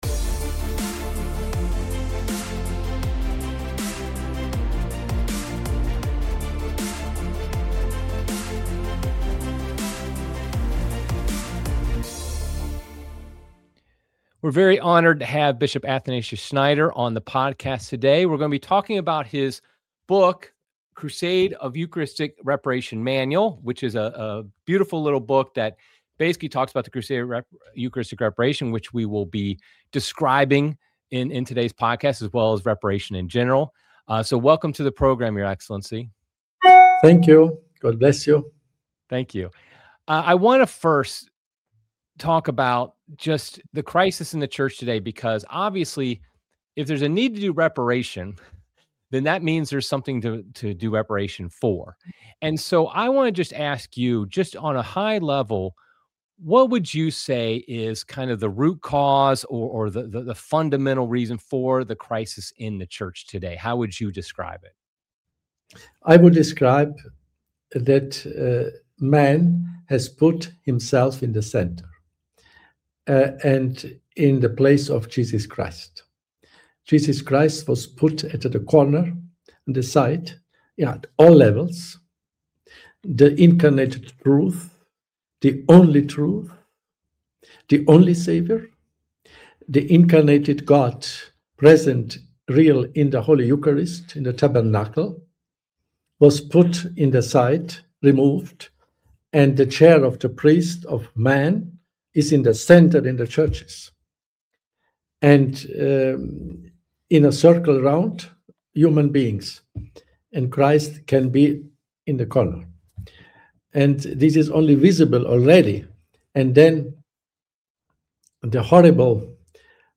Bishop Athanasius Schneider joins us to talk about the sources of today's widespread indifference and disbelief toward the Eucharist, and what we can do to combat it.